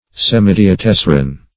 Search Result for " semidiatessaron" : The Collaborative International Dictionary of English v.0.48: Semidiatessaron \Sem`i*di`a*tes"sa*ron\, n. (Mus.) An imperfect or diminished fourth.